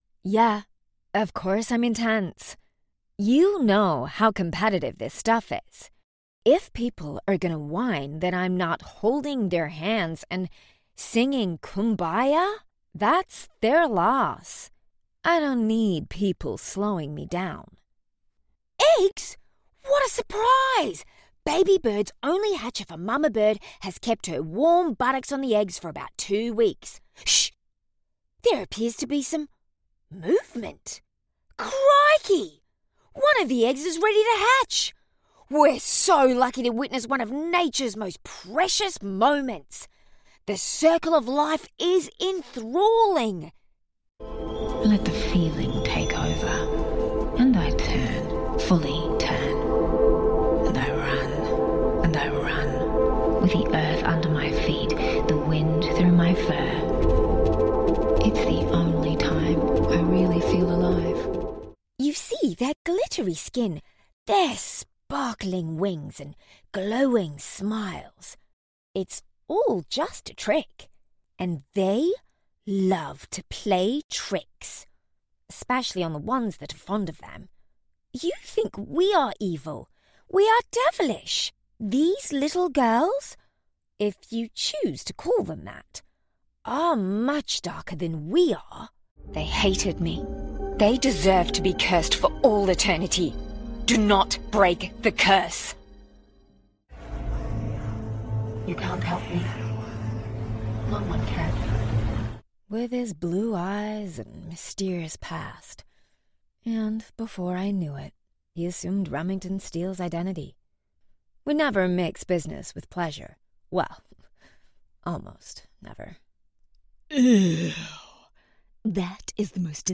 English (Australia)
Natural, Playful, Reliable, Friendly, Warm